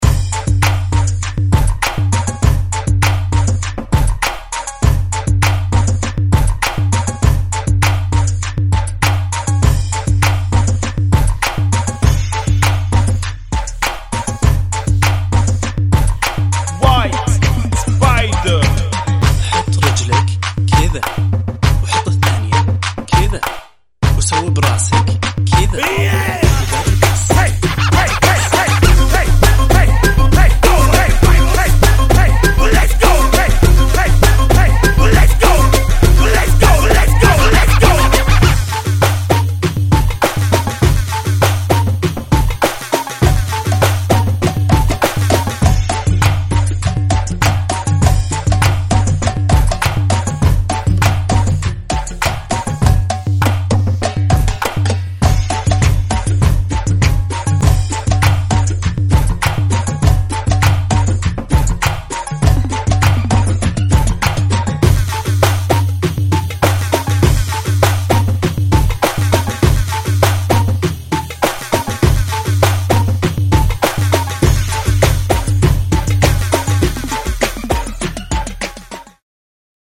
Funky [ 100 Bpm ]